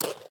Minecraft Version Minecraft Version snapshot Latest Release | Latest Snapshot snapshot / assets / minecraft / sounds / mob / parrot / eat2.ogg Compare With Compare With Latest Release | Latest Snapshot
eat2.ogg